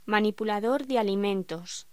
Locución: Manipulador de alimentos
voz
Sonidos: Hostelería